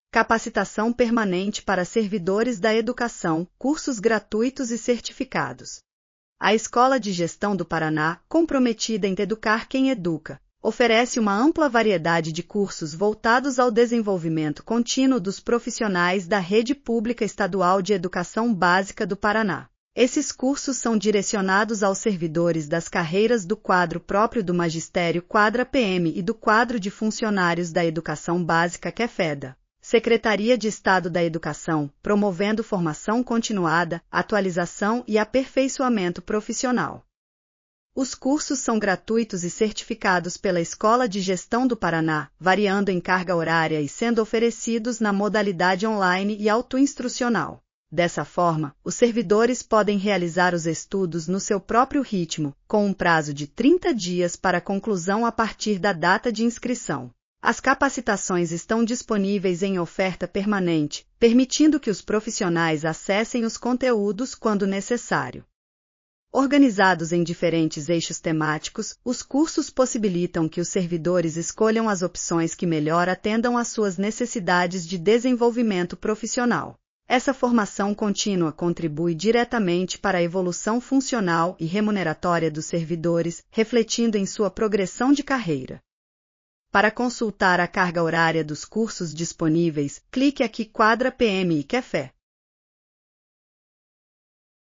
audionoticia_capacitacao_permanente_seed.mp3